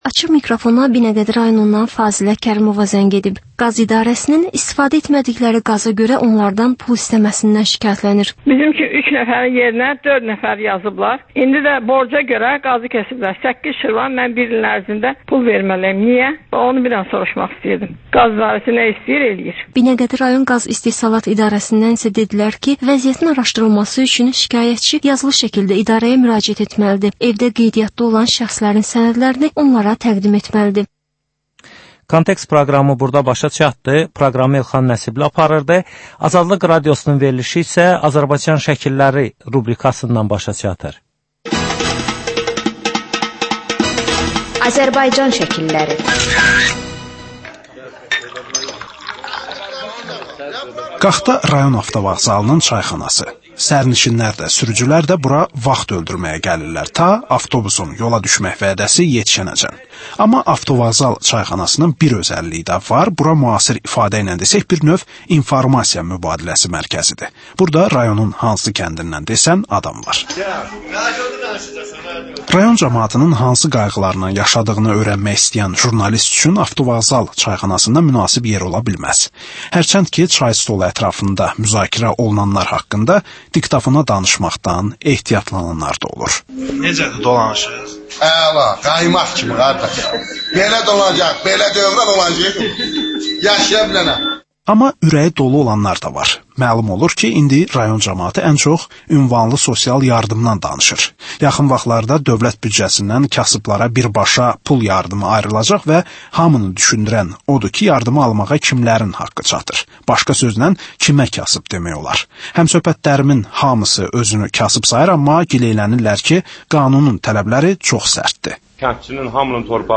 Azərbaycan rayonlarından reportajlar